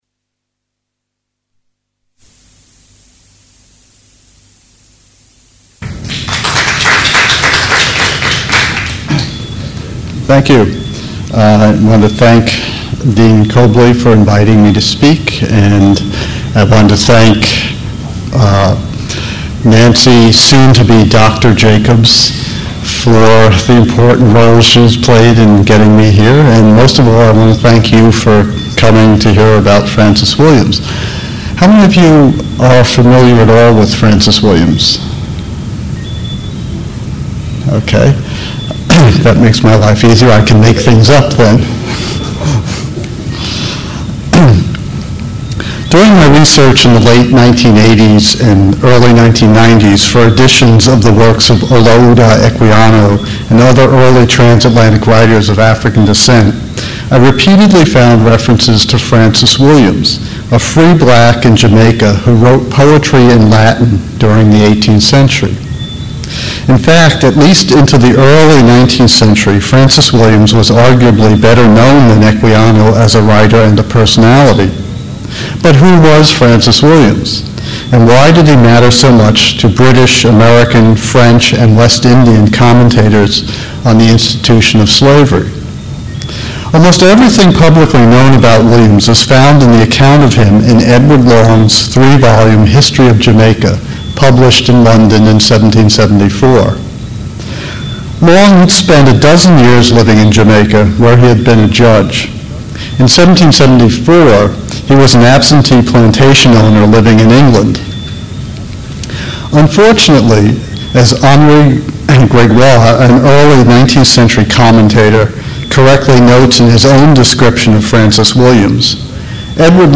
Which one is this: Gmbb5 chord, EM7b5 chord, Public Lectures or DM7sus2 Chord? Public Lectures